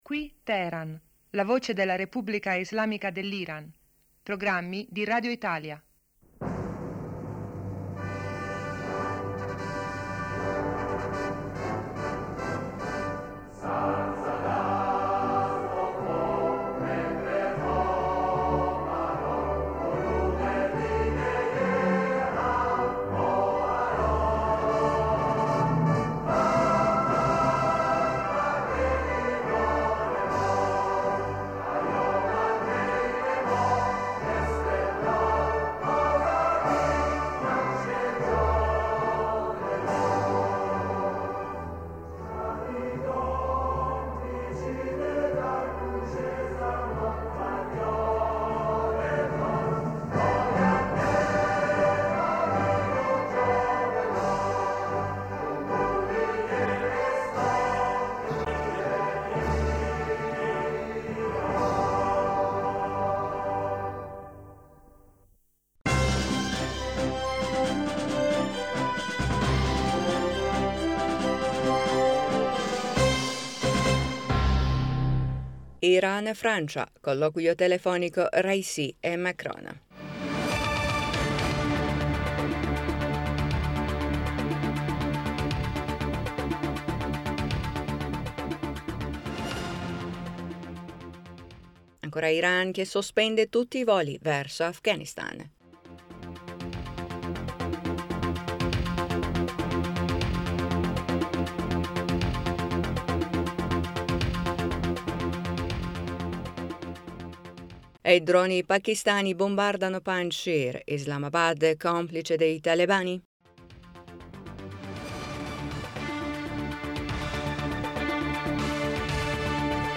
Ecco i titoli più importanti del nostro radiogiornale:1-Iran sospende tutti i voli verso Afghanistan,2-I droni pakistani bombardano Panjshir.